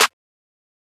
MZ Snareclap [Metro #4].wav